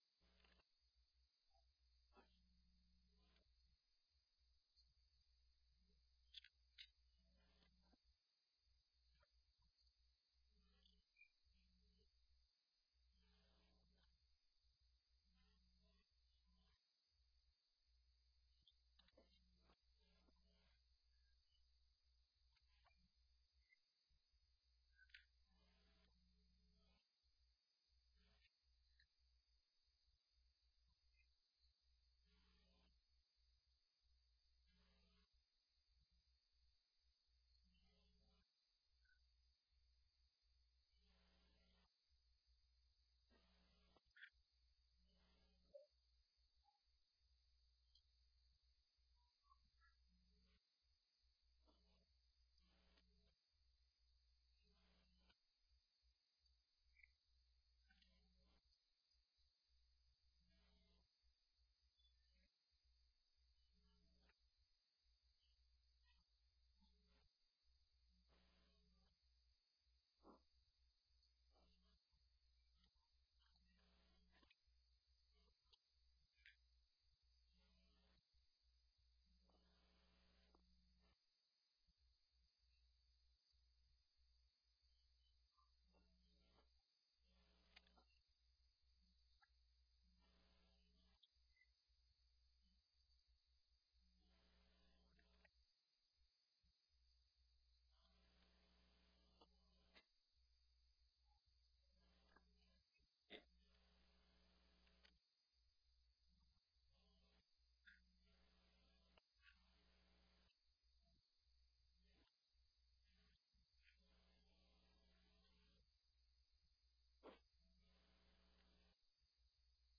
Christmas-Eve-Message-2025.mp3